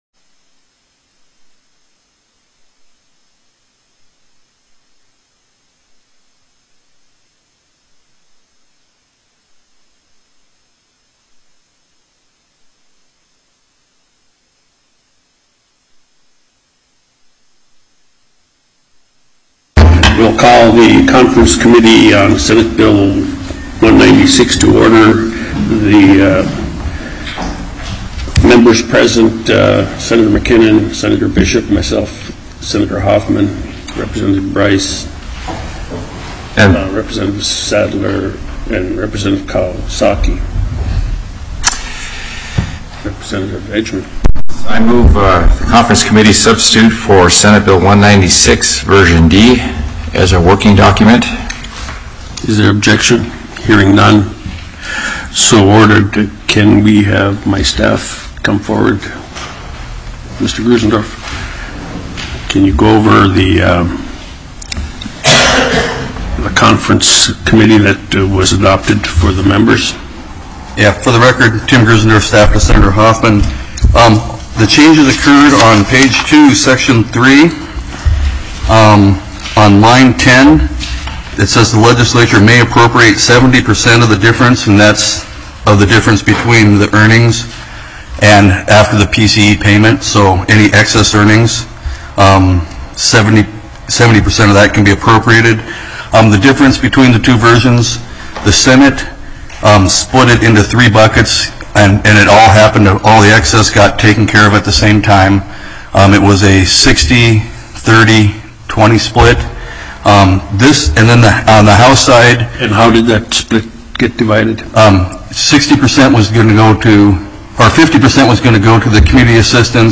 04/28/2016 09:00 AM House CONFERENCE COMMITTEE ON SB196